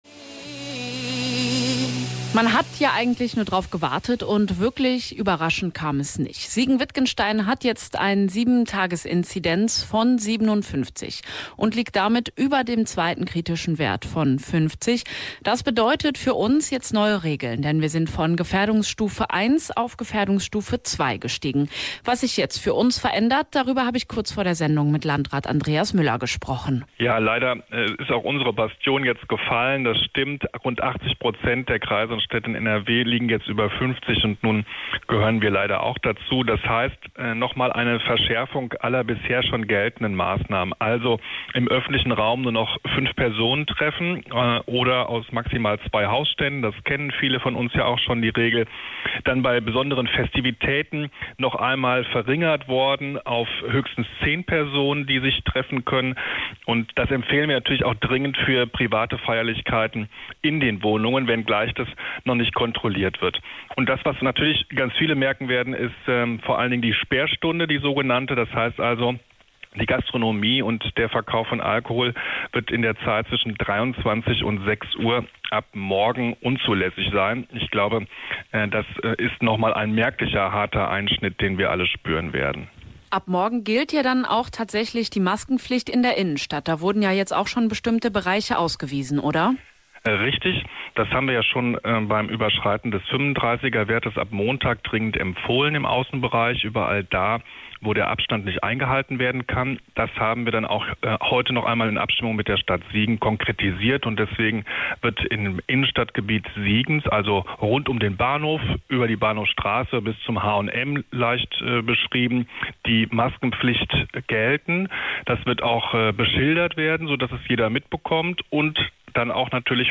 Hier könnt Ihr Euch das aktuelle Interview mit Landrat Andreas Müller anhören: